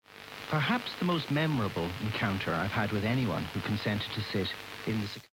无线电干扰
描述：来自不明来源的无线电干扰
标签： 无线电 失真 干扰
声道立体声